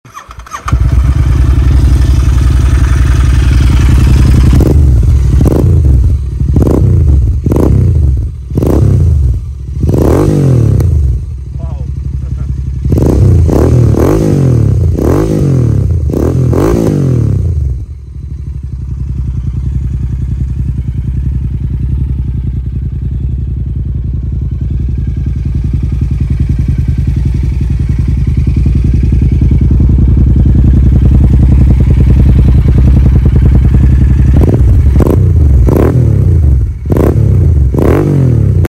Growler Exhaust without dB Killer
• Sporty note with deep bass
With Dug Dug Growler Slip-ons, you will get the benefit of increased performance and the bellowing, throaty growl of a SS-304 premium exhaust.
growler-without-dB.mp3